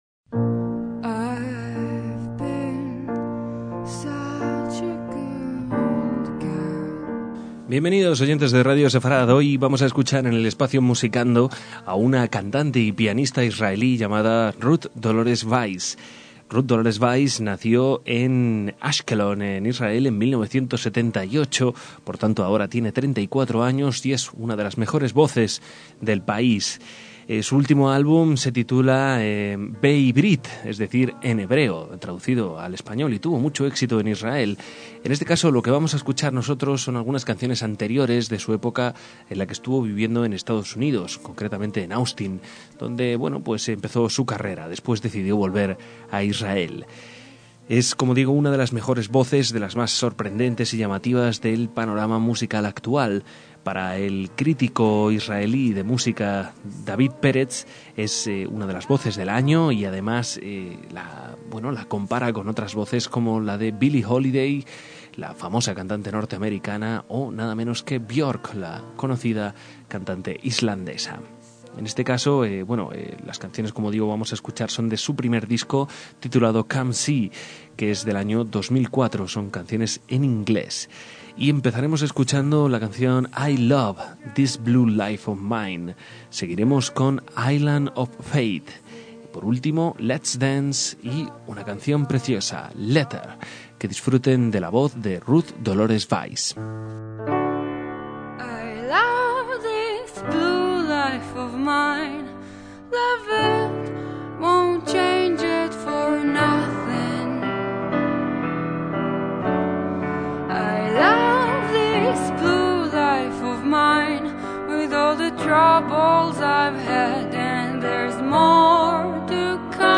en inglés y sólo acompañada de piano.